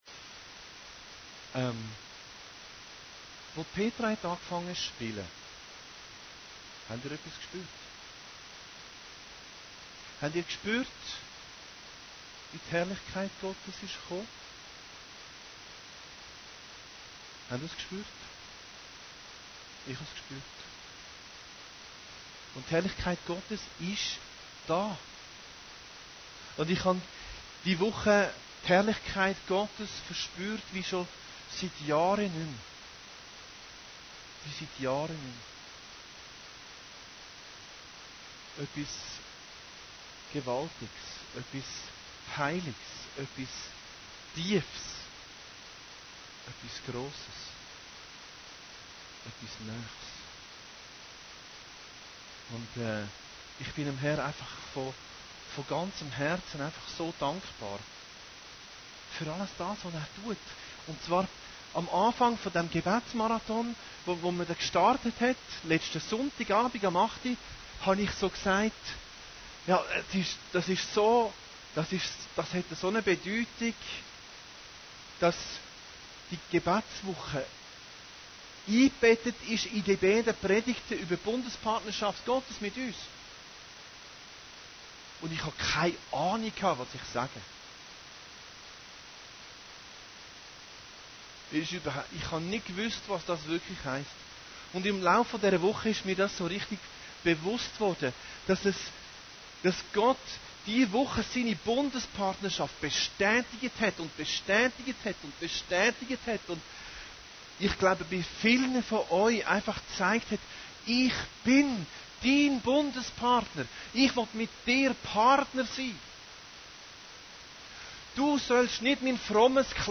Predigten Heilsarmee Aargau Süd – Bundespartner Gottes Teil 2